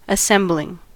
assembling: Wikimedia Commons US English Pronunciations
En-us-assembling.WAV